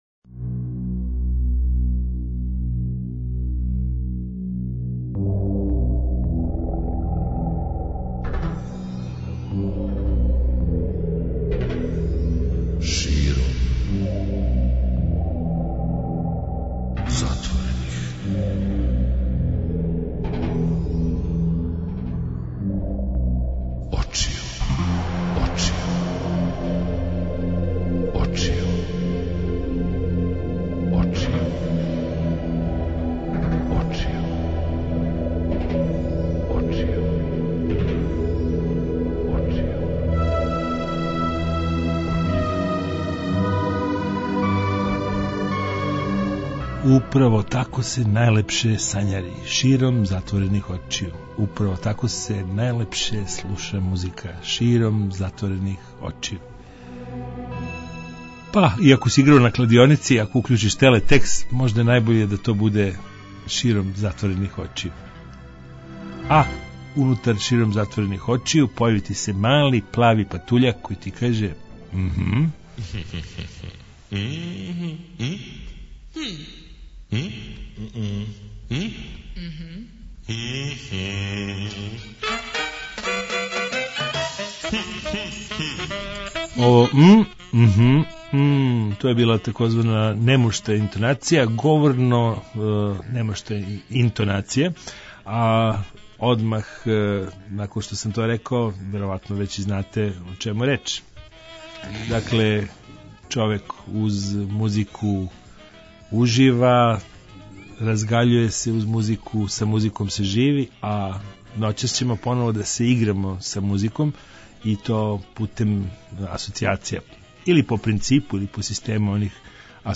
А како изгледа када се са музиком играмо по систему асоцијација? Ноћас ћемо то да урадимо у сарадњи са вама који се налазите са оне праве стране радио апарата.